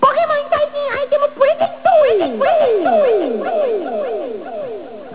今日のジングル(wav : 40.2KB)